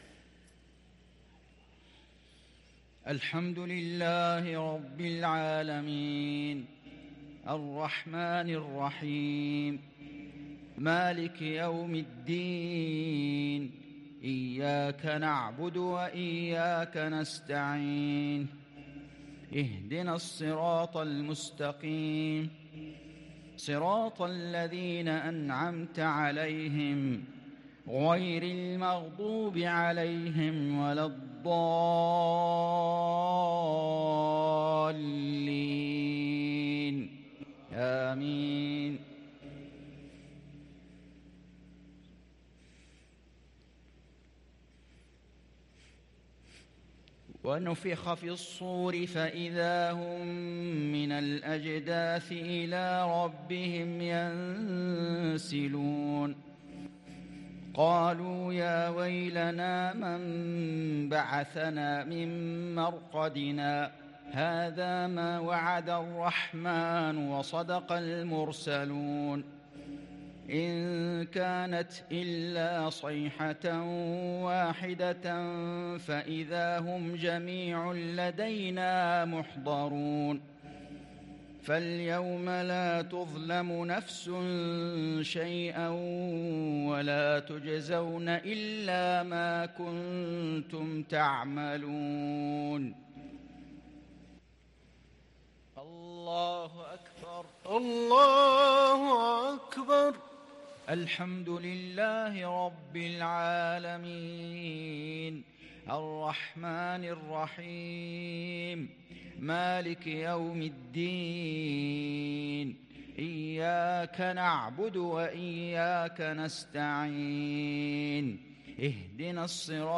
صلاة المغرب ٣ محرم ١٤٤٤هـ من سورة يس | Maghrib prayer from Surah Ya Sin 1-8-2022 > 1444 🕋 > الفروض - تلاوات الحرمين